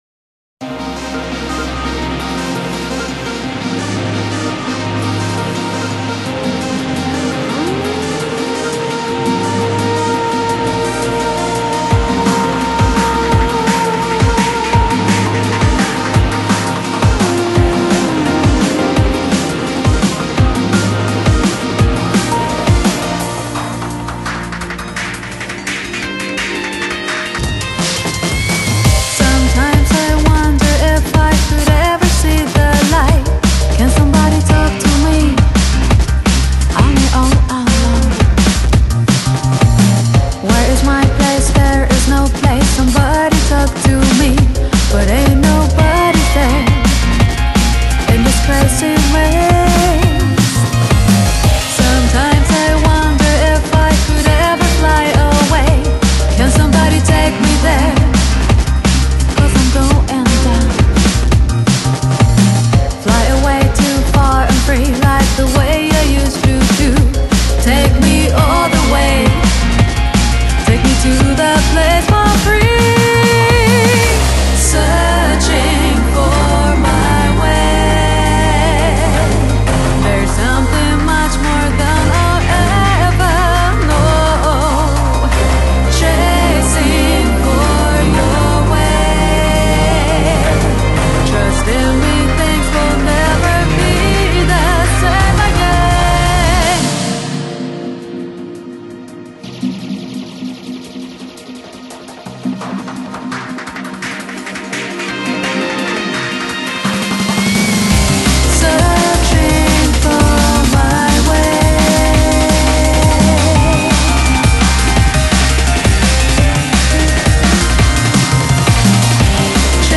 BPM85-170
Audio QualityPerfect (High Quality)
Comments[MIDNIGHT DnB]